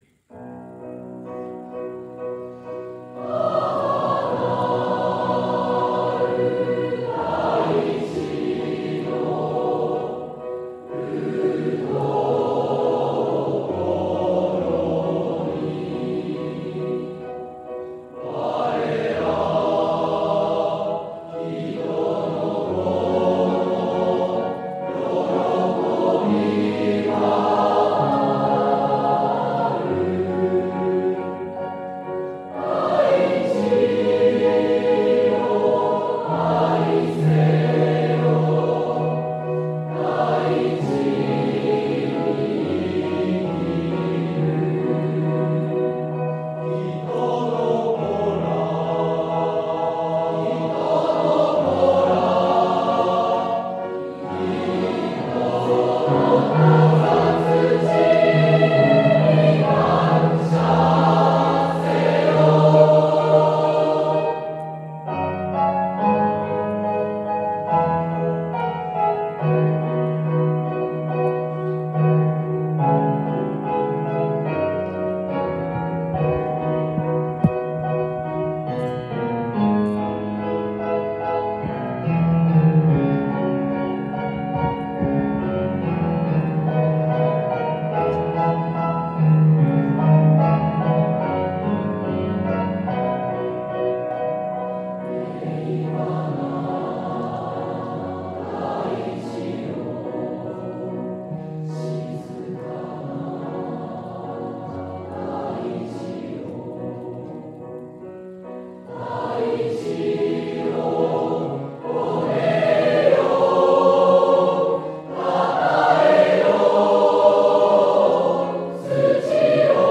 gassyou.mp3